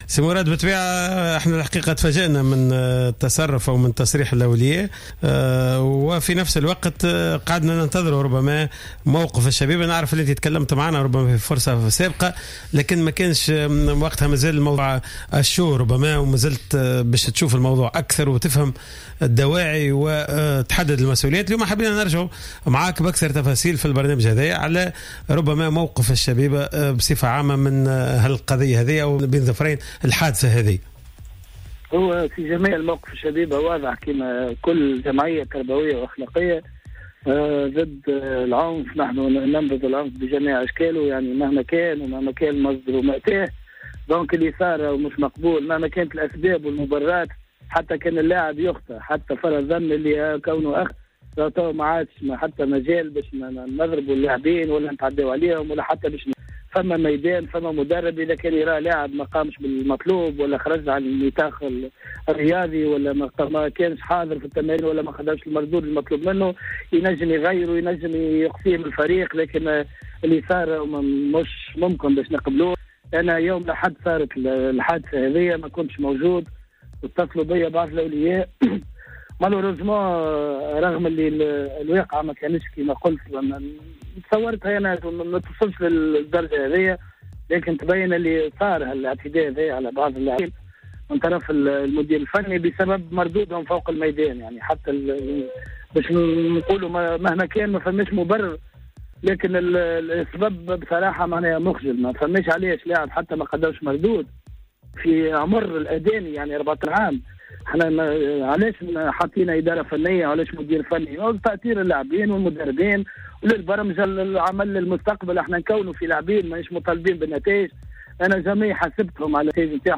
مداخلة في برنامج cartes sur table